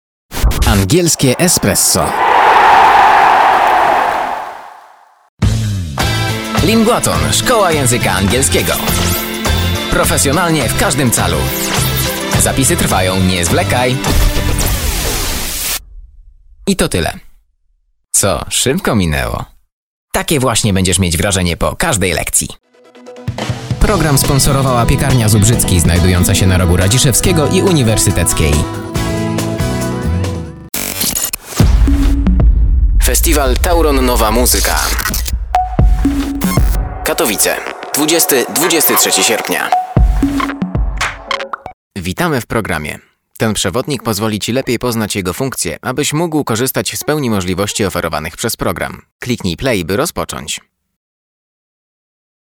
Male 20-30 lat
Demo lektorskie